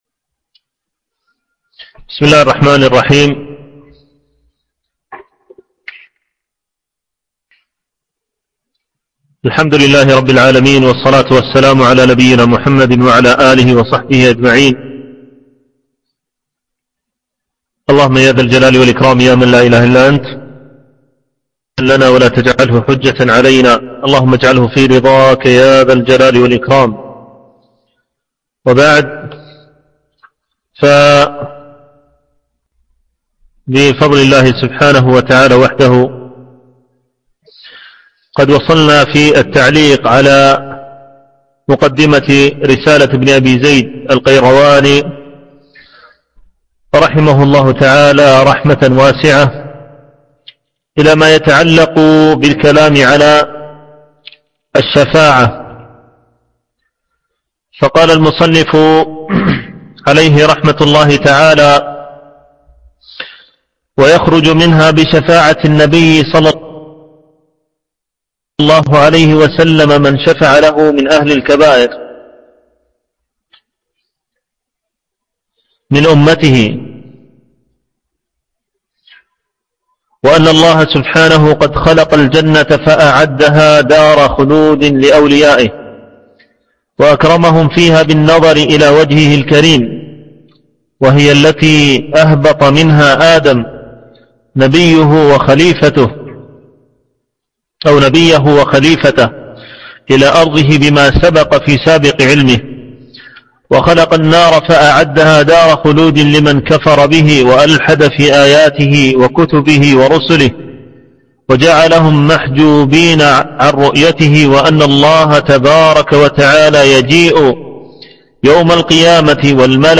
العنوان: الدرس الخامس عشر
التنسيق: MP3 Mono 22kHz 40Kbps (CBR)